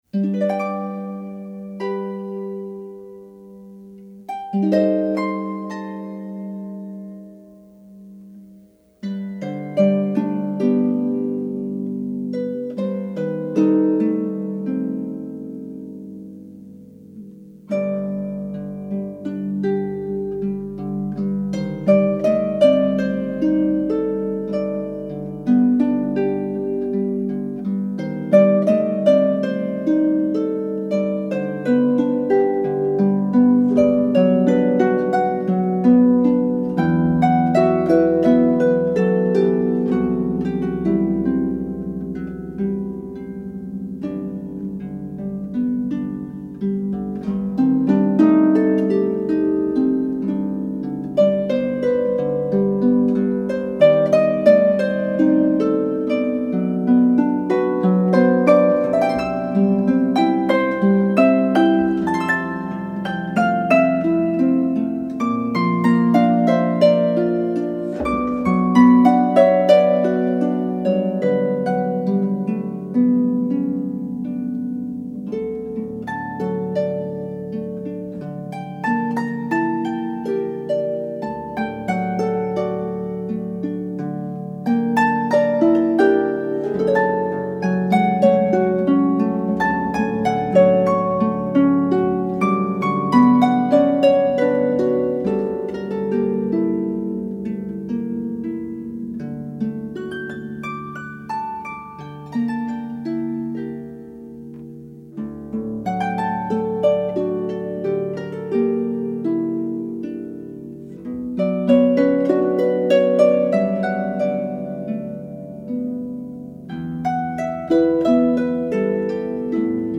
The music adds an immediate calmness to a room.
Sounds very much like a movie theme. So romantic…